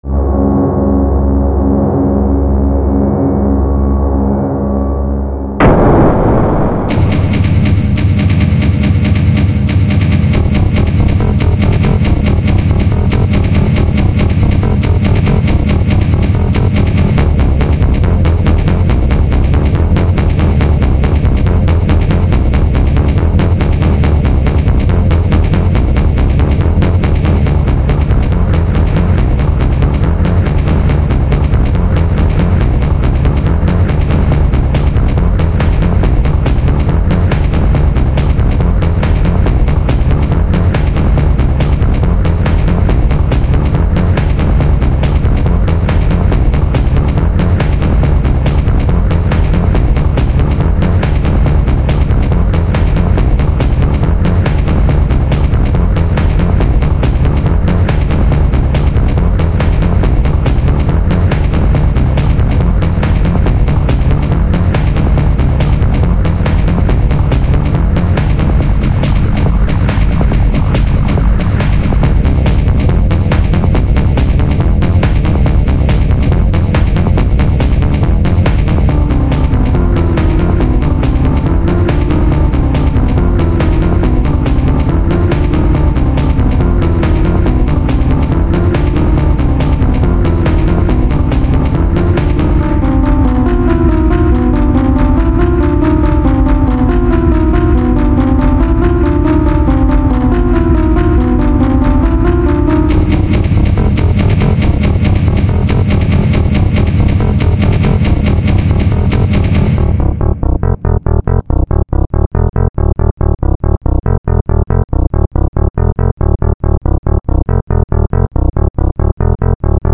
It's a dark theme, with quite some variation at the start, but unfortunately it pretty soon falls for the monotonous repeating-theme trap again, where it eventually completely loses this Tiberian Sun feel.